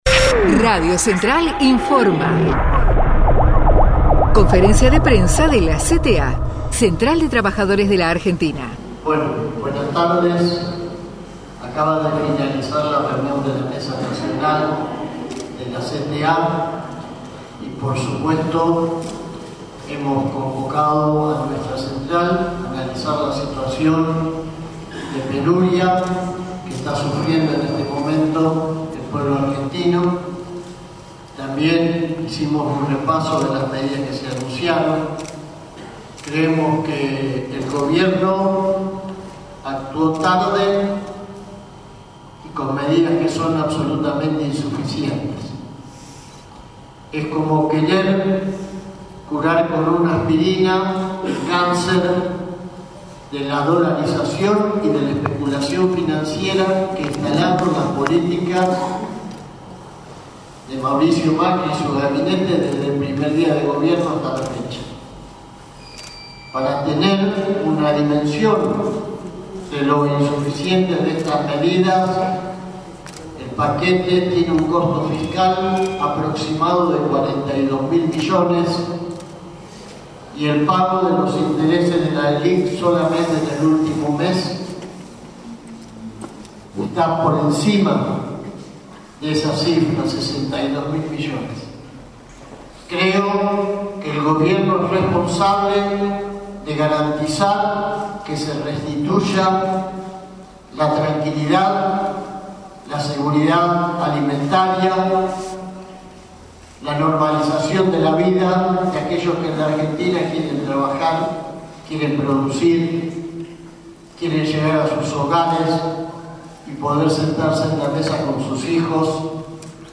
CONFERENCIA DE PRENSA CTA